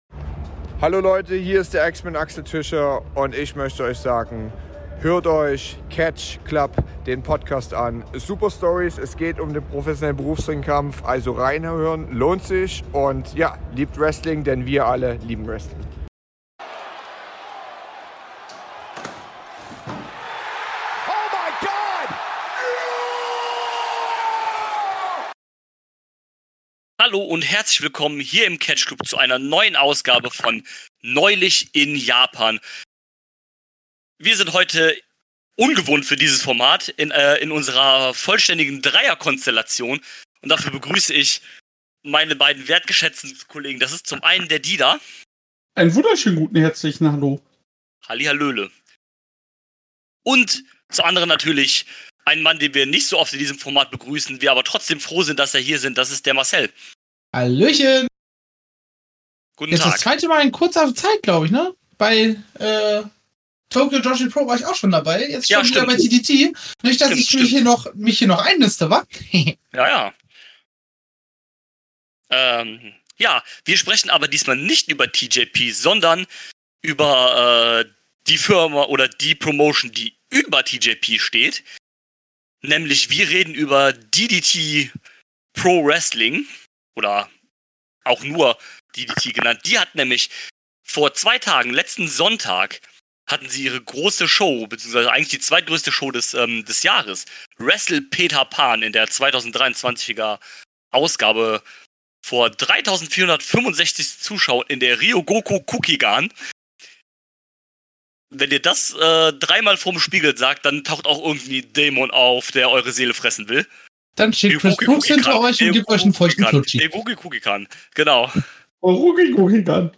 In der 3er Runde haben wir über die Show gesprochen und sind auf alles eingegangen. viel Spaß!